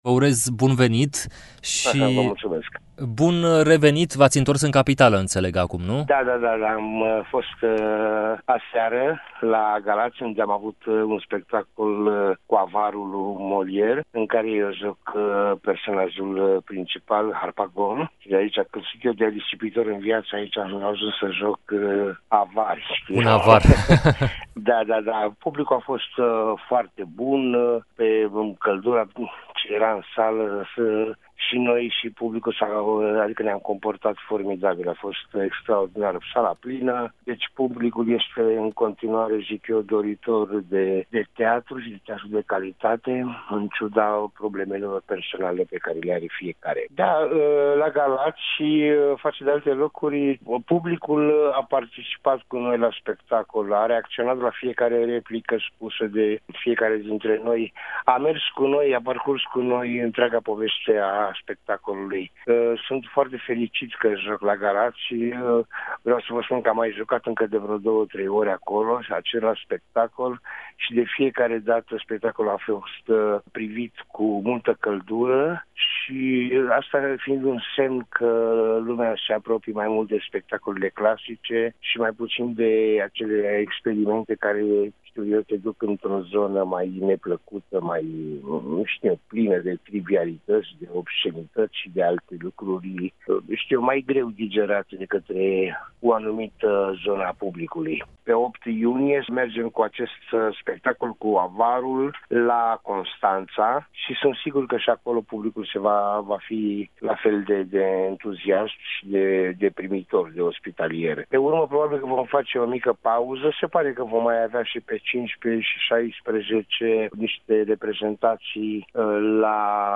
Actorul Tudorel Filimon este invitatul rubricii ”Sus cortina!”, de la ora 17.15, în emisiunea ”Drum cu prioritate”, chiar în ziua în care EUROPA FM împlinește 15 ani.